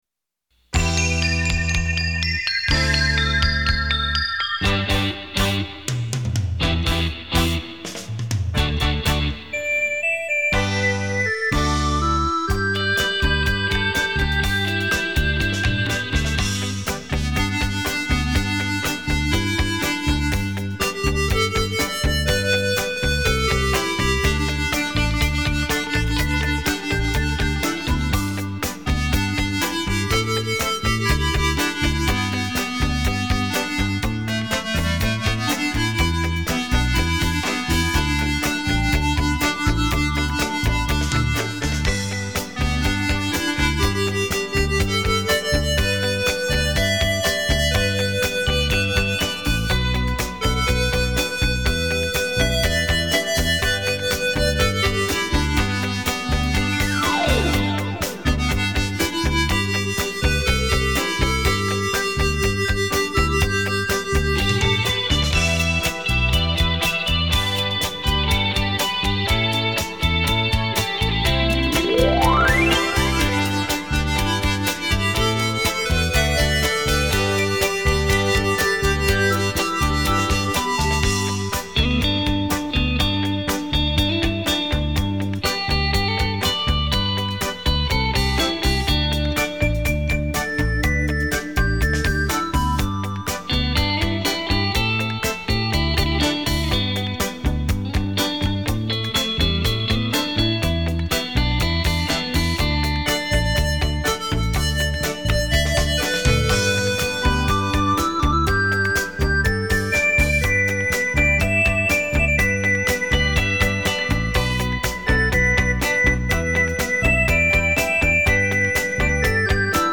超時空繞場立體音效 發燒音樂重炫
百萬名琴魅力大出擊，旋律優美，曲曲沁心